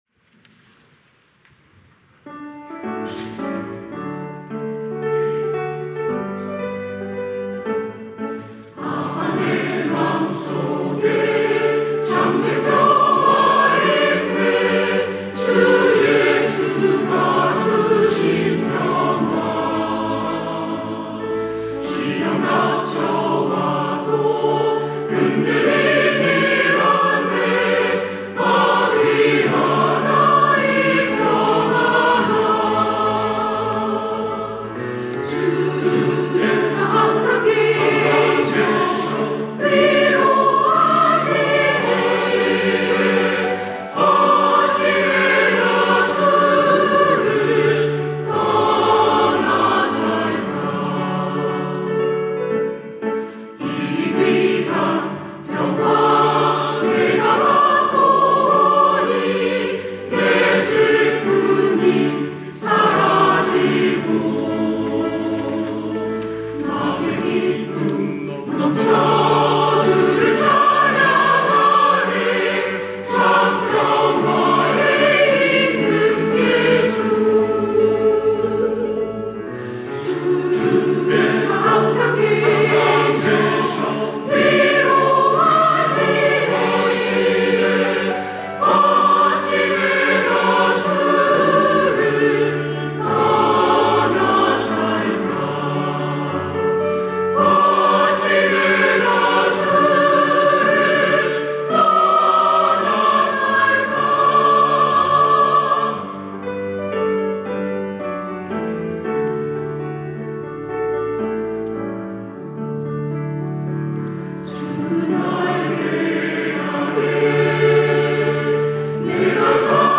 시온(주일1부) - 아 내 맘속에
찬양대 시온